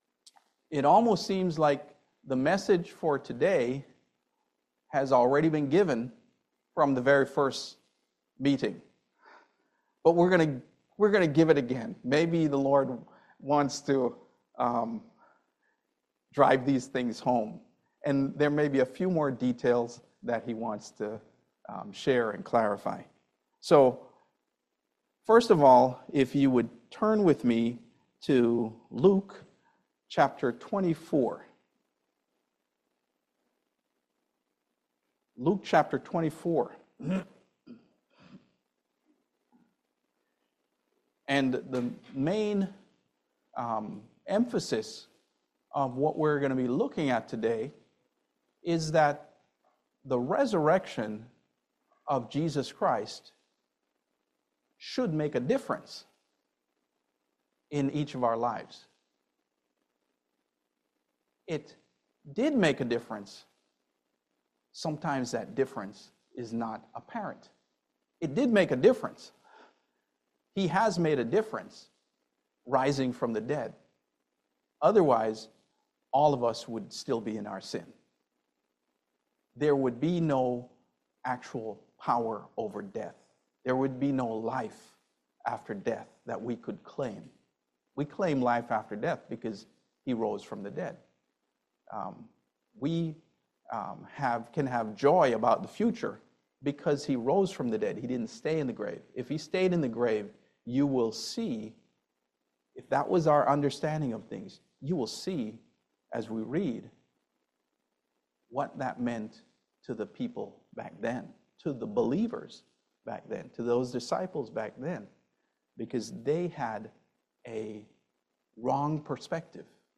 Luke 24:13-35 Service Type: Family Bible Hour Live victoriously because of Christ’s resurrection.